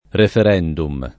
[ refer $ ndum ]